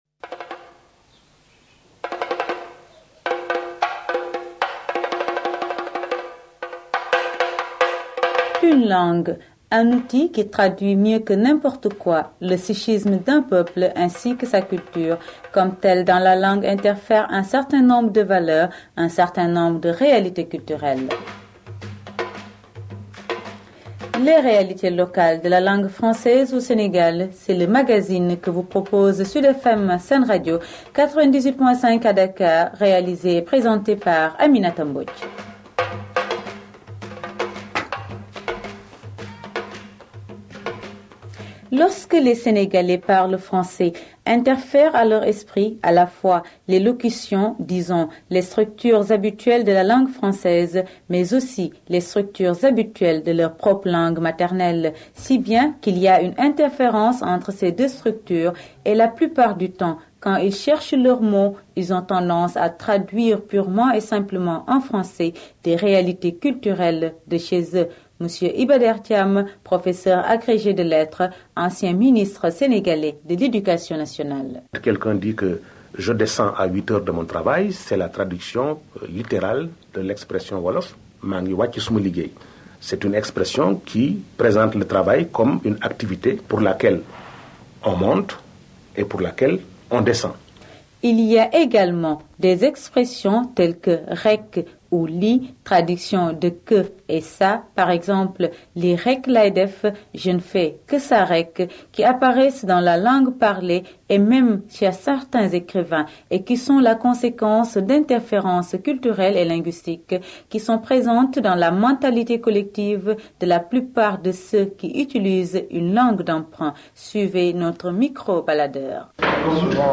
En compagnie du professeur Iba der Thiam, ancien Ministre de l'éducation nationale du Sénégal, Radio Sud FM SenRadio à Dakar se penche sur les couleurs du français au Sénégal. (Archives Radio Suisse Internationale, 1997, série «Le français parlé»)